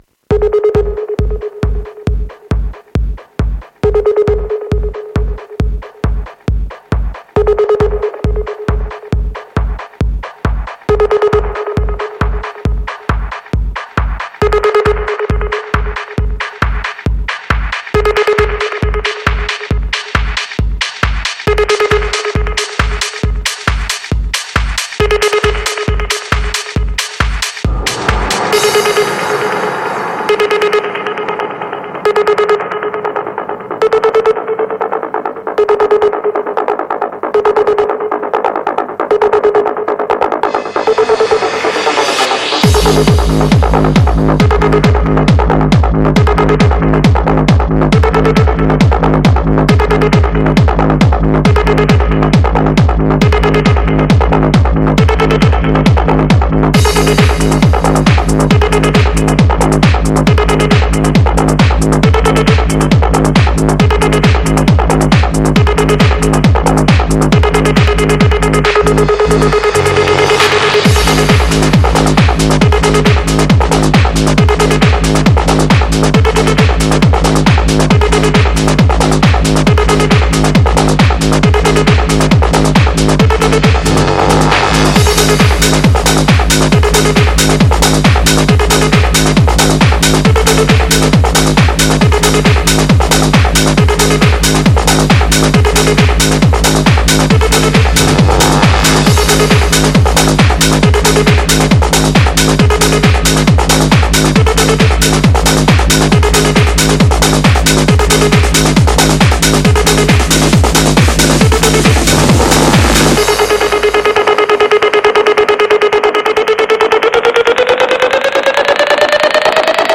Жанр: Hard Trance